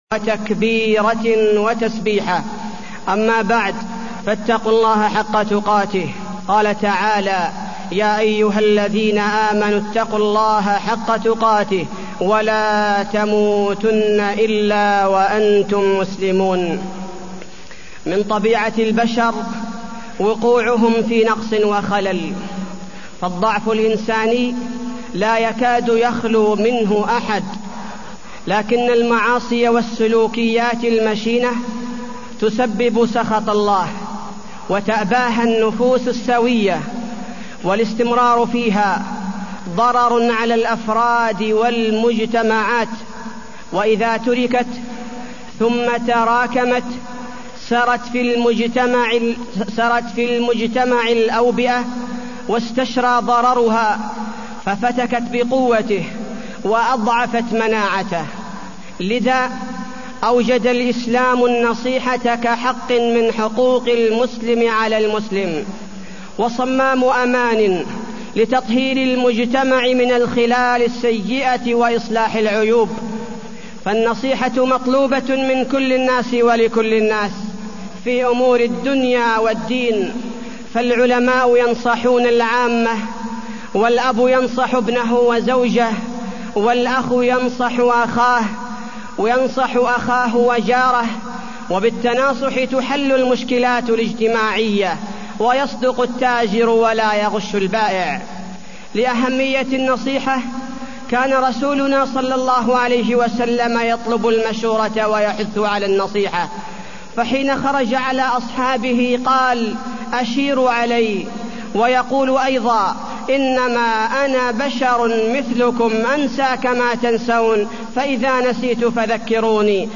تاريخ النشر ١٦ محرم ١٤٢١ هـ المكان: المسجد النبوي الشيخ: فضيلة الشيخ عبدالباري الثبيتي فضيلة الشيخ عبدالباري الثبيتي النصيحة